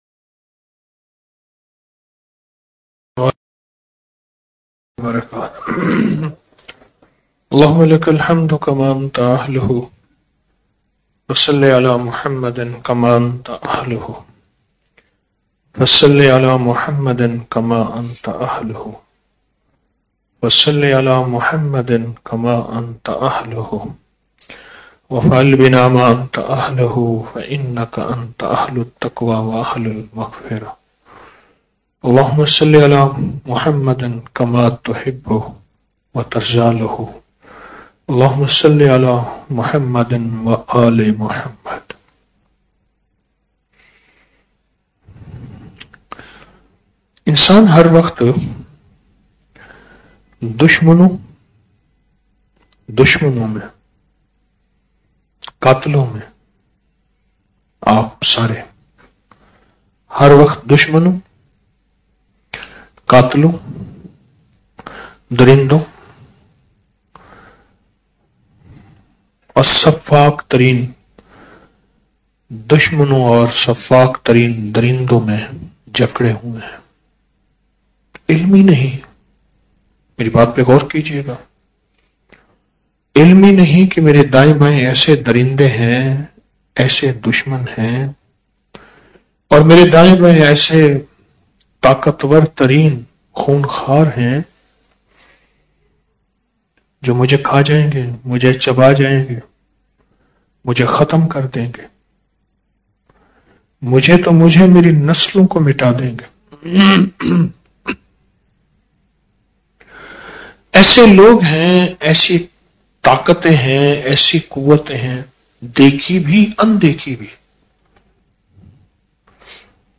Audio Speech - Shab e Jumma Mehfil - 09 January 2025